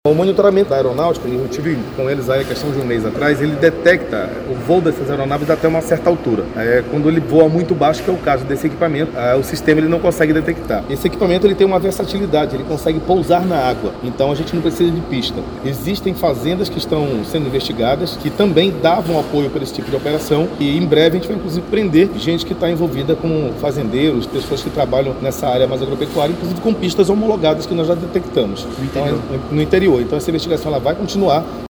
SONORA02_VINICIUS-ALMEIDA.mp3